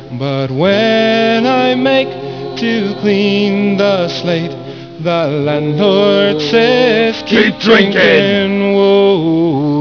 vocals, violin
vocals, bodhran
-- vocals, guitar, pennywhistle
vocals, octave mandolin, hammered dulcimer